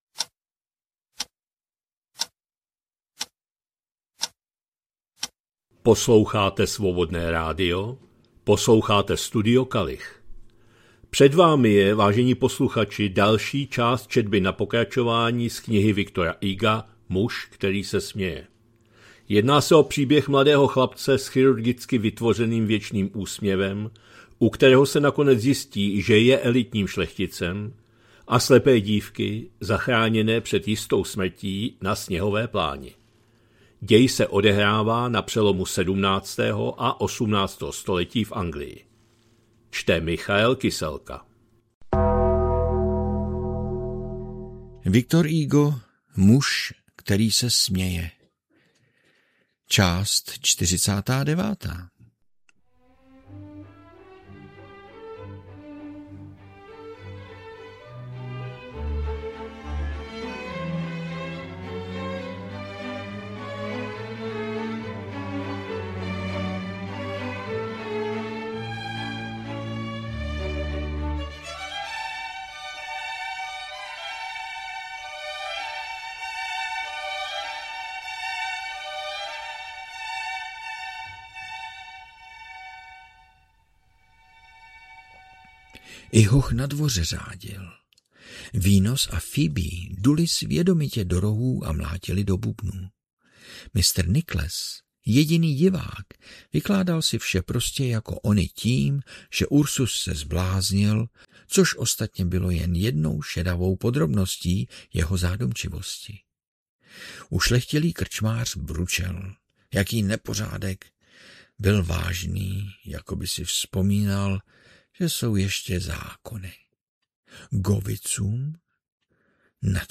2026-02-20 - Studio Kalich - Muž který se směje, V. Hugo, část 49., četba na pokračování